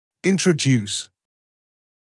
[ˌɪntrə’djuːs][ˌинтрэ’дйуːс]вводить в употребление, использовать; представлять